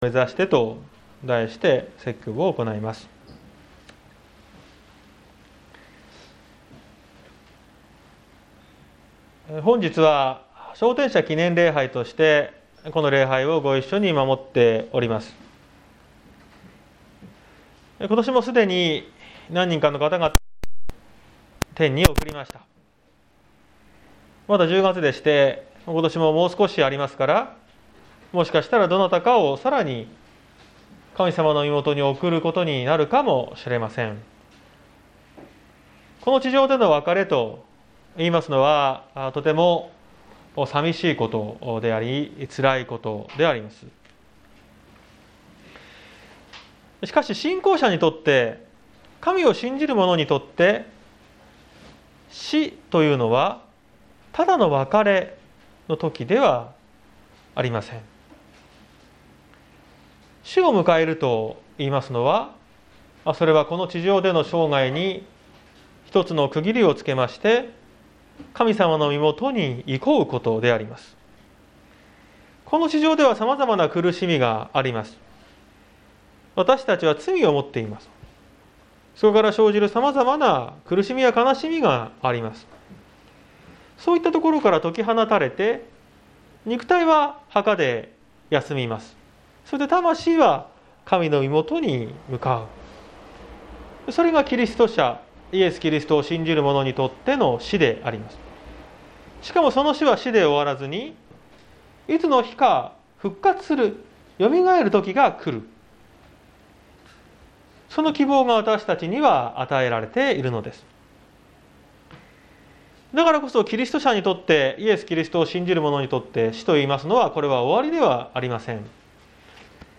2021年10月17日朝の礼拝「死の先にある都を目指して」綱島教会
説教アーカイブ。